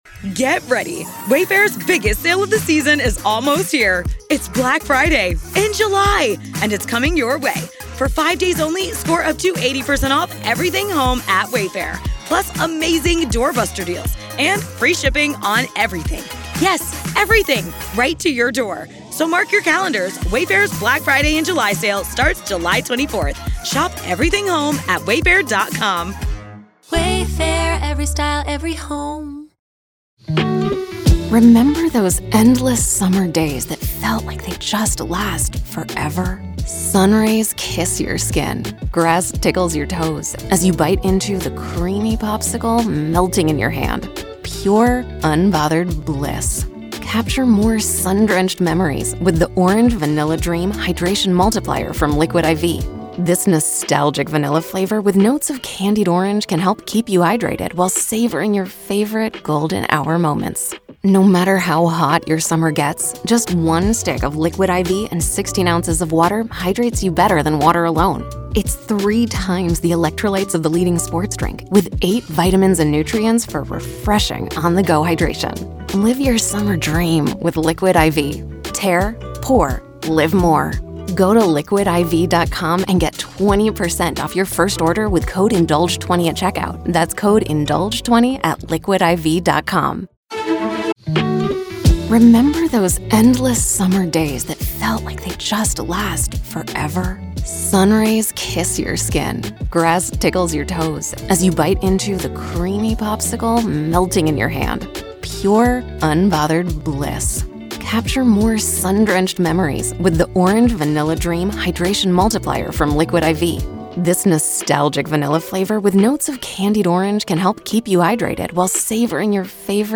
BREAKING NEWS-RAW COURT AUDIO-June 2025 Start Date Set for Bryan Kohberger Trial in University of Idaho Murders Case